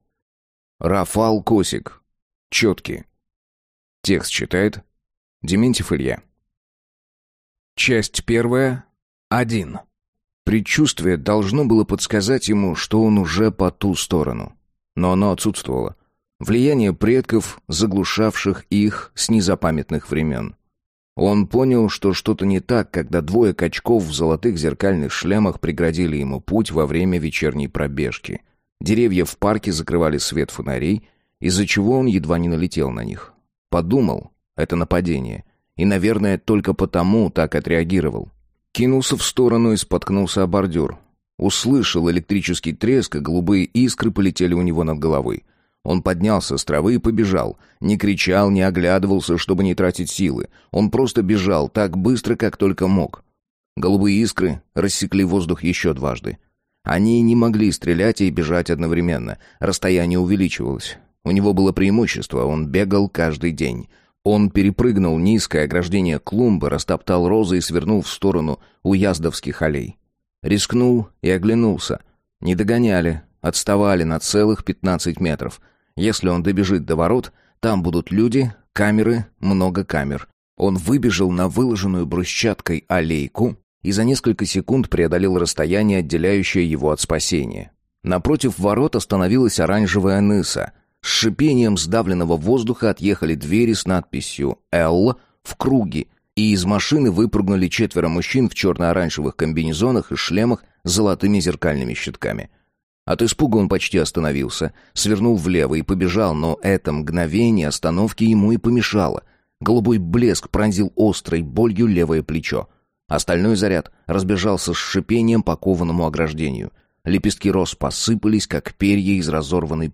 Аудиокнига Четки | Библиотека аудиокниг